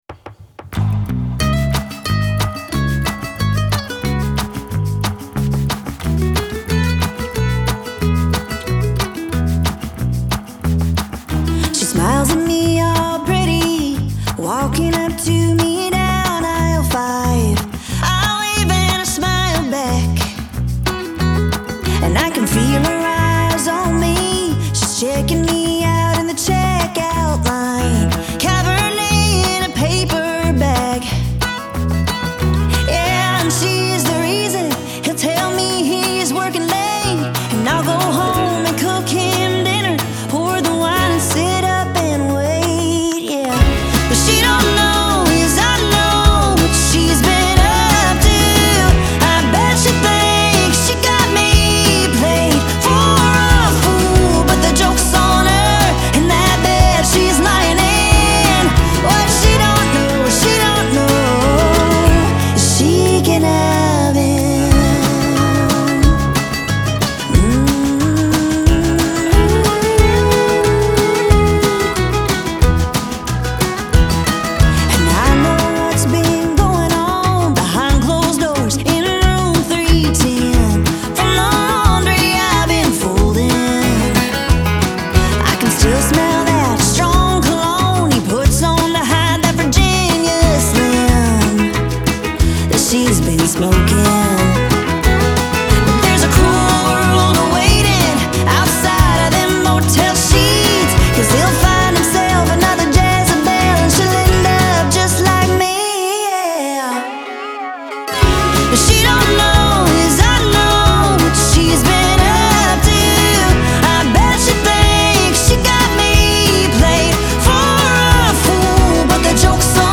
Genre : Country, Folk, Blues